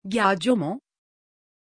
Pronuncia di Giacomo
pronunciation-giacomo-tr.mp3